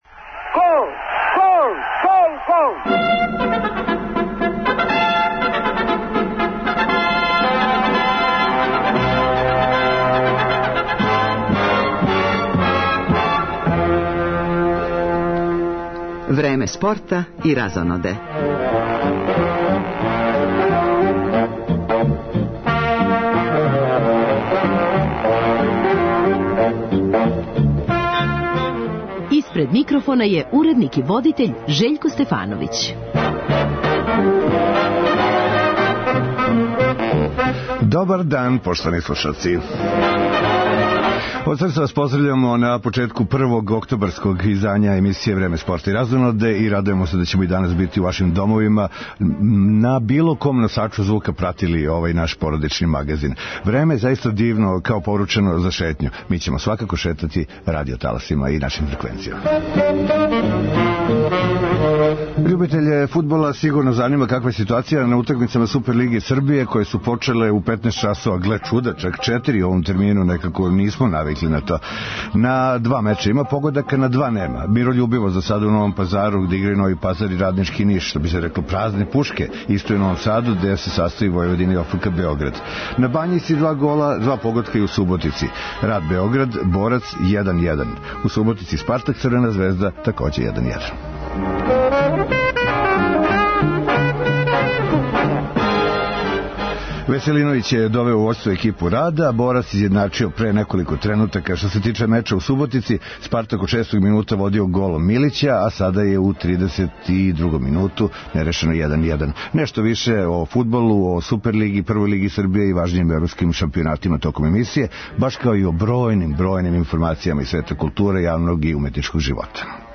Одбојкашице Србије вечерас од 18.30 играју полуфинални меч против селекције Русије у Ротердаму, на Европском првенству. Током емисије, у више наврата, чућемо изјаве наших играчица, али и одбојкашких стручњака.